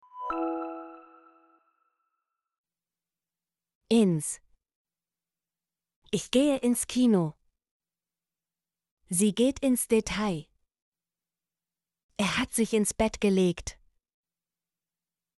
ins - Example Sentences & Pronunciation, German Frequency List